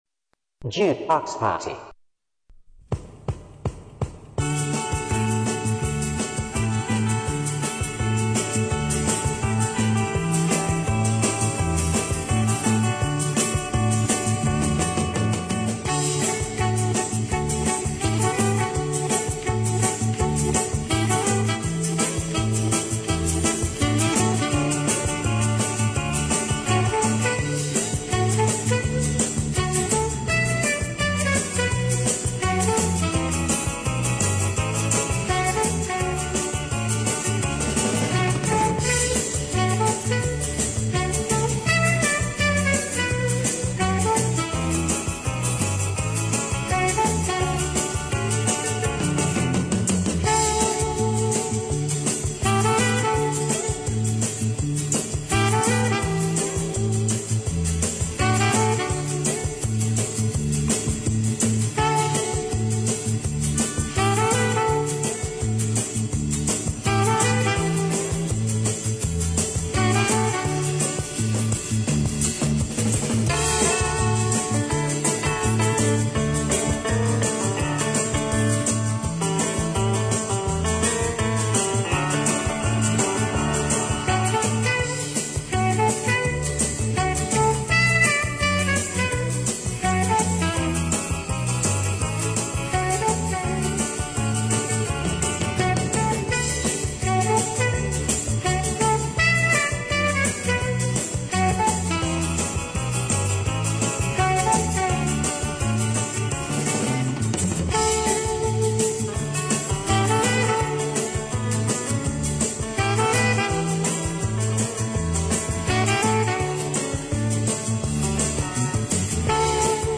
sessions #4 - Saxophone
Saxophone-instrum-session01- (30min.)